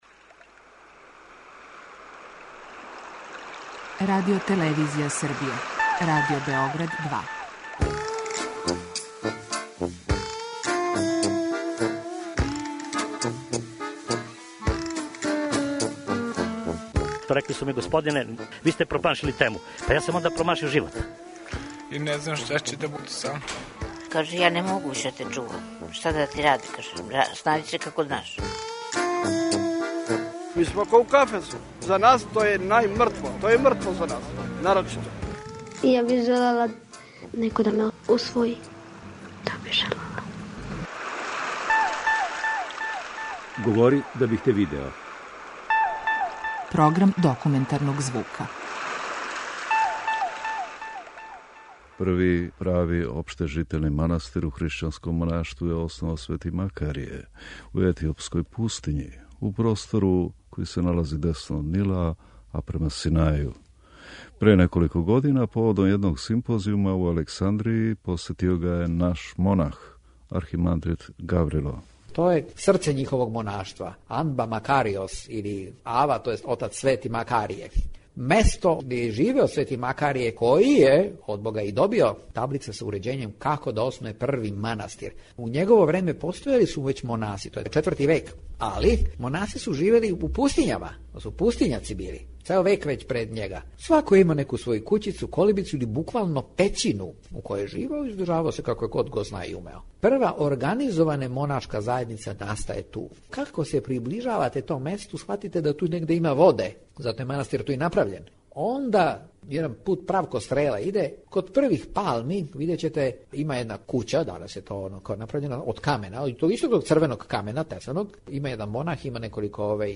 Документарни програм
преузми : 10.75 MB Говори да бих те видео Autor: Група аутора Серија полусатних документарних репортажа, за чији је скупни назив узета позната Сократова изрека: "Говори да бих те видео".
Један манастир у Египту, установљен пре шеснаест векова, први од те врсте установа основаних у хришћанском монаштву, посетио је пре неког времена један наш монах. Говори нам о својим импресијама о овом манастиру, као и о помесној Цркви којој припада...